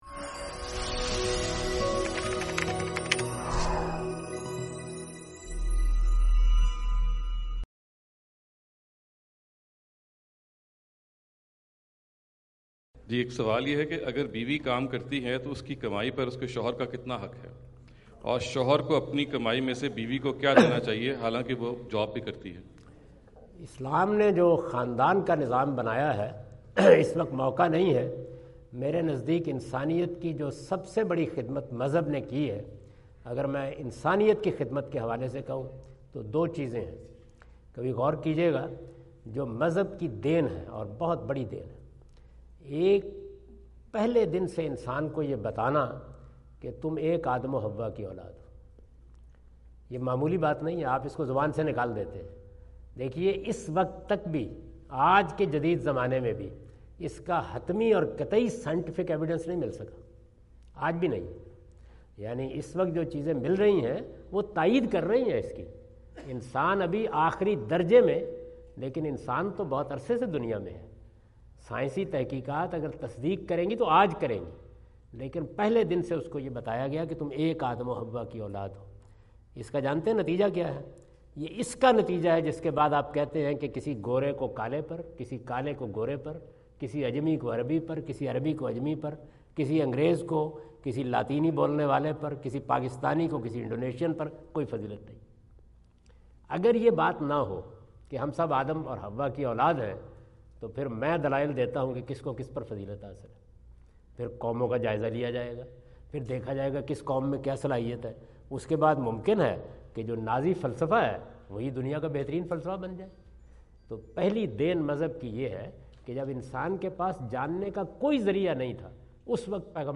Category: English Subtitled / Questions_Answers /
Javed Ahmad Ghamidi answer the question about "Does Husband Have any Right on Wife's Income?" asked at Aapna Event Hall, Orlando, Florida on October 14, 2017.